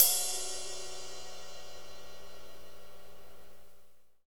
Index of /90_sSampleCDs/Northstar - Drumscapes Roland/DRM_Pop_Country/KIT_P_C Wet 1 x
CYM P C RI0T.wav